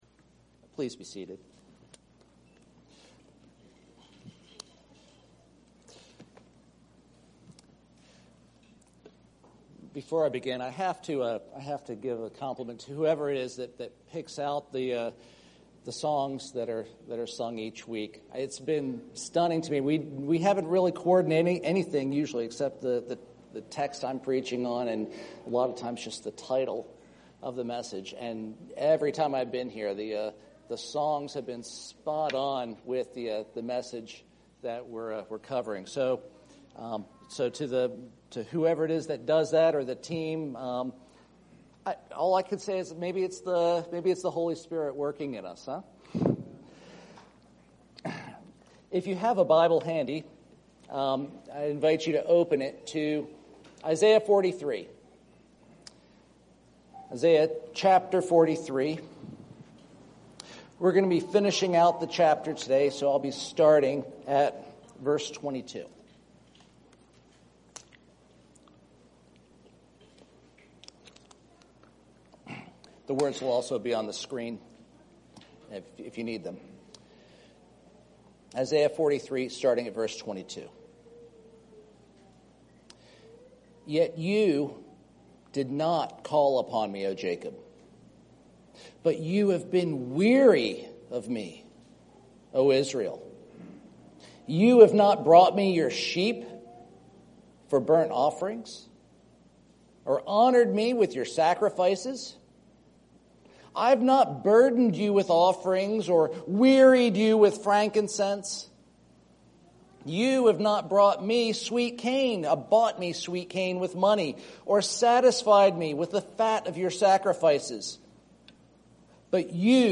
A Presbyterian Church (PCA) serving Lewiston and Auburn in Central Maine